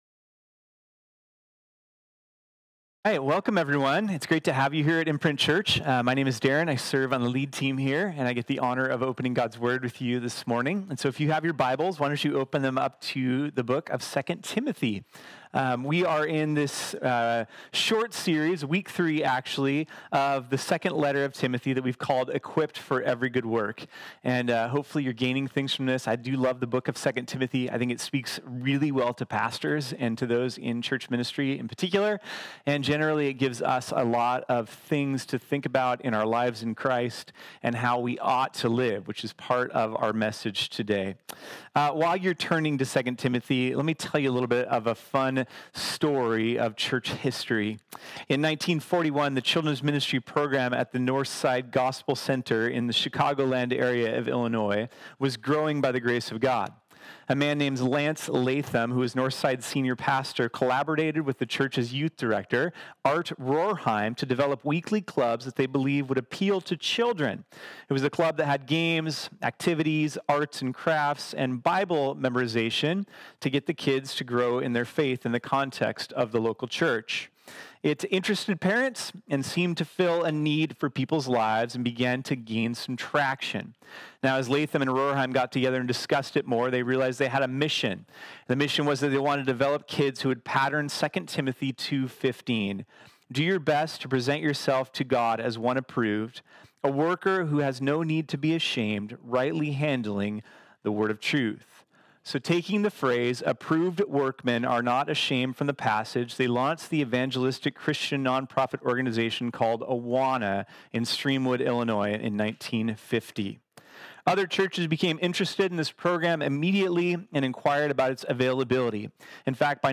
This sermon was originally preached on Sunday, August 18, 2019.